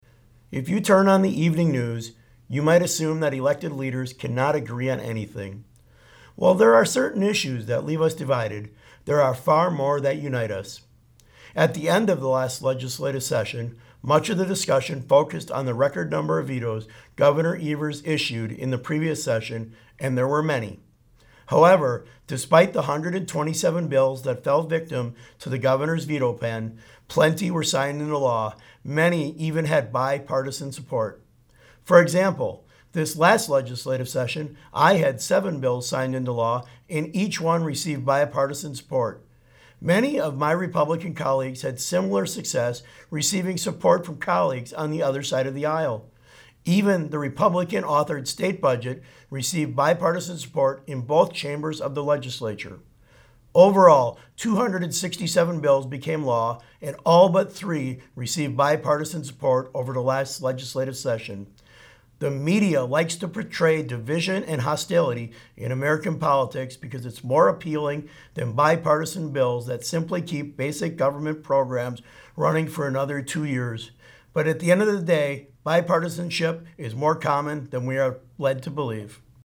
Weekly GOP radio address: Sen. Feyen says bipartisanship is the norm, not the exception - WisPolitics